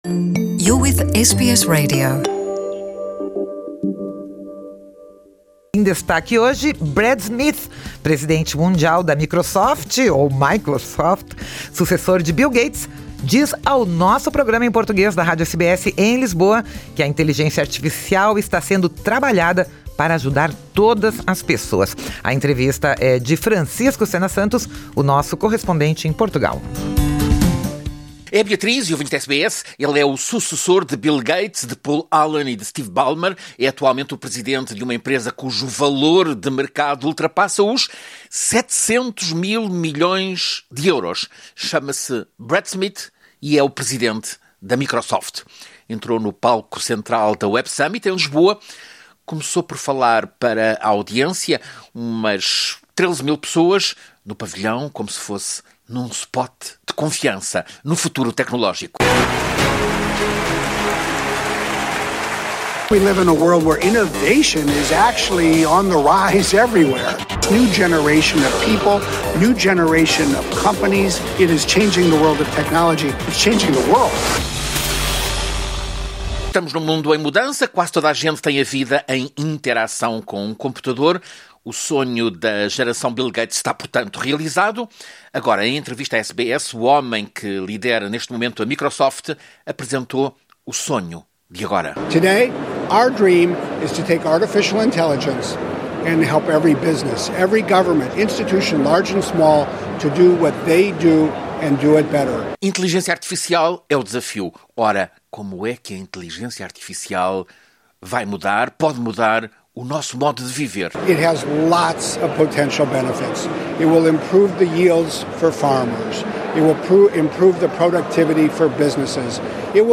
Brad Smith, presidente mundial da Microsoft, sucessor de Bill Gates, disse ao nosso Programa em Português da Rádio SBS, no Web Summit em Lisboa, que a inteligência artificial está sendo trabalhada para ajudar todas as pessoas, empresários, governos e cidadãos".
Entrevista